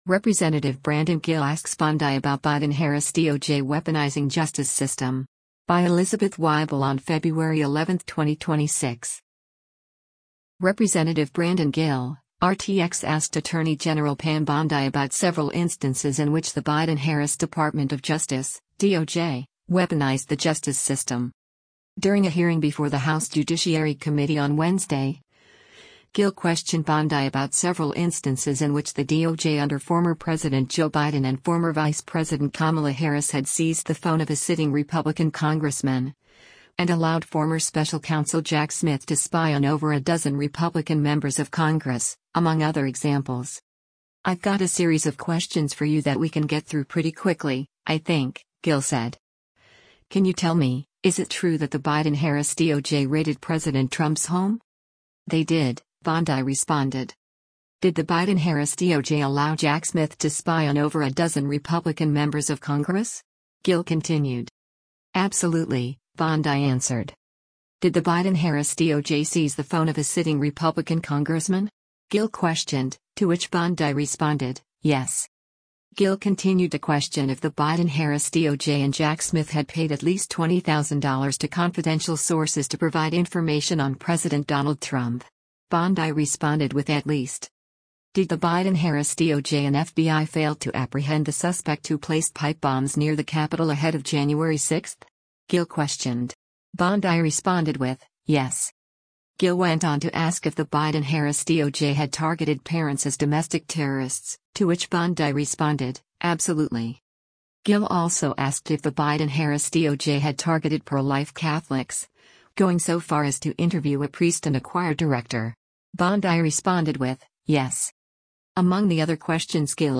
During a hearing before the House Judiciary Committee on Wednesday, Gill questioned Bondi about several instances in which the DOJ under former President Joe Biden and former Vice President Kamala Harris had seized “the phone of a sitting Republican Congressman,” and allowed former special counsel Jack Smith “to spy on over a dozen Republican members of Congress,” among other examples.